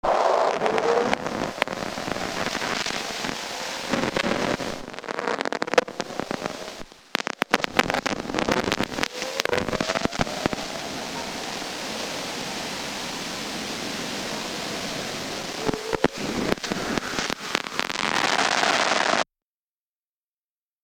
(photo right) The same crackling and popping as before, and a slow curve that indicates saturation with water.
recording looks the way one would expect in calm conditions, and there is certainly audio qualities to match.